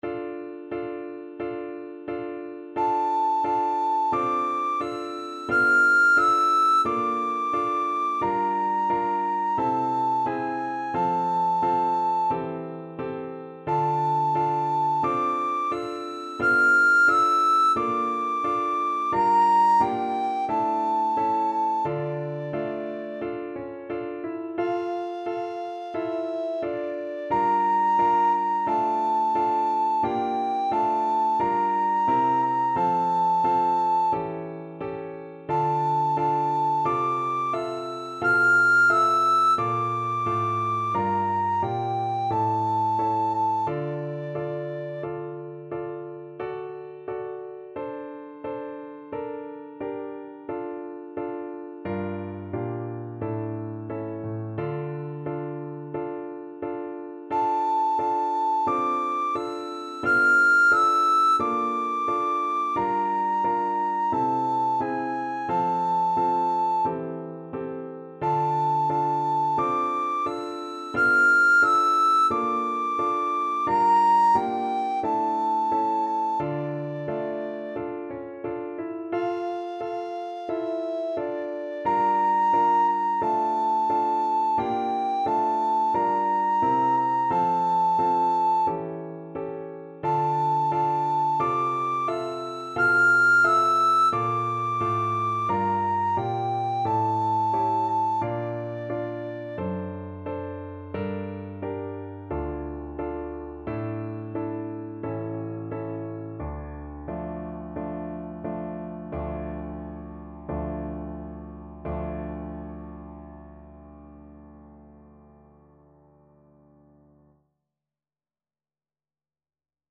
Free Sheet music for Soprano (Descant) Recorder
4/4 (View more 4/4 Music)
Andante =c.88
D6-F7
Classical (View more Classical Recorder Music)
Japanese